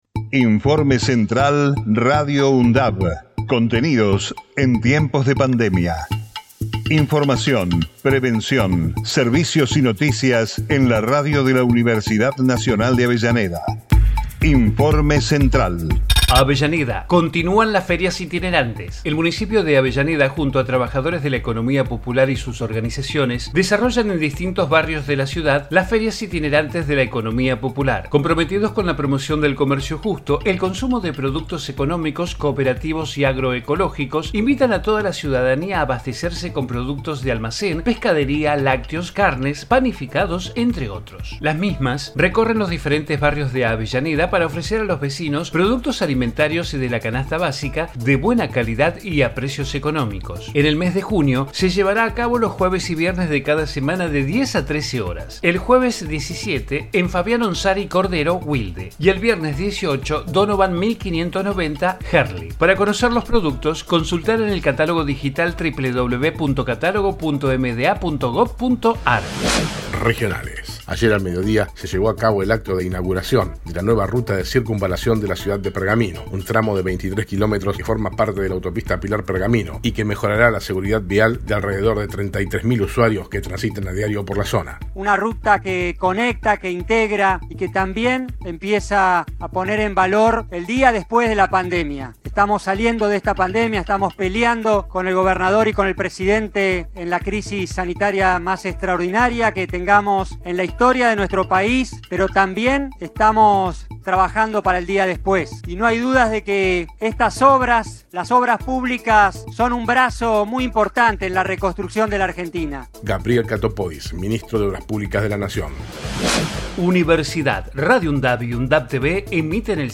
COVID-19 Informativo en emergencia 16 de JUNIO 2021 Texto de la nota: Informativo Radio UNDAV, contenidos en tiempos de pandemia. Información, prevención, servicios y noticias locales, regionales y universitarias.